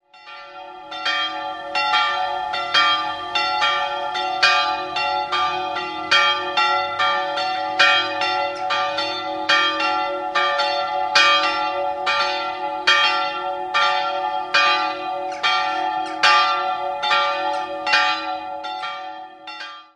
2-stimmiges Geläut: es''-f''
Freystadt_Mariahilf.mp3